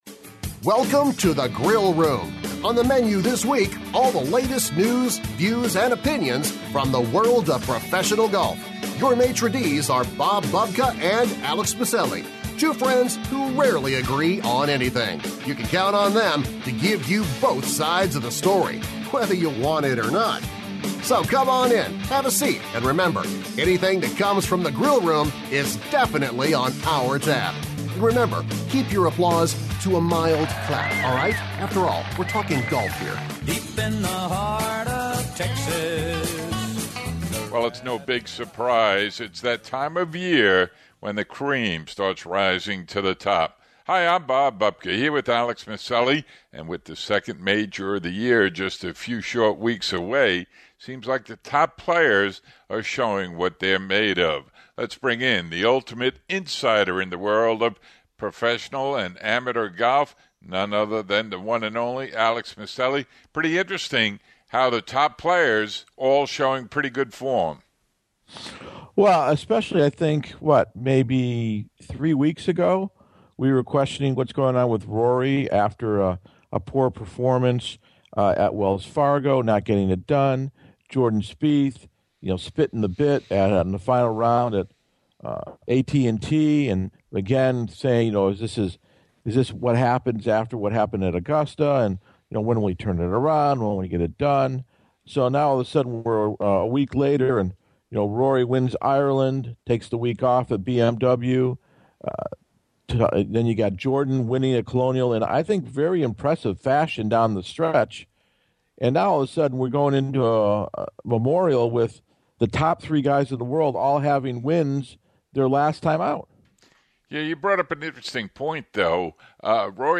Feature Interviews